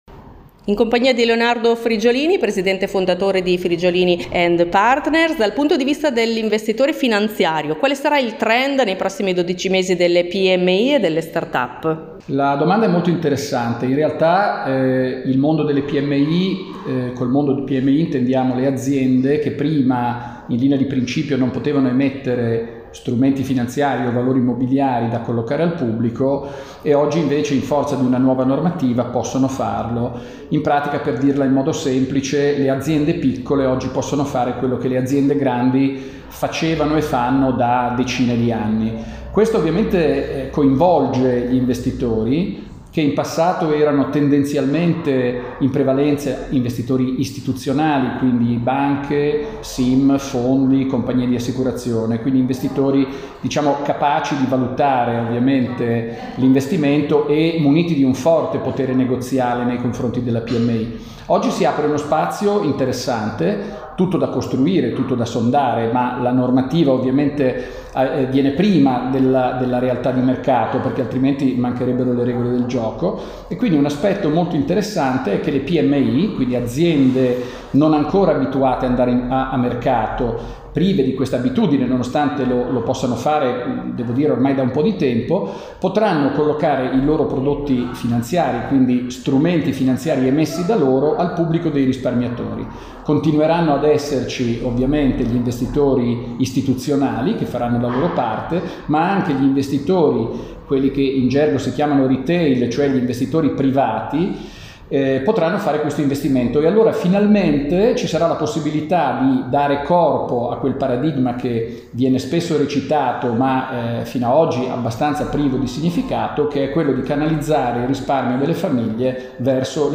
I Portici Hotel – Via Indipendenza, 69 – Bologna
l’intervista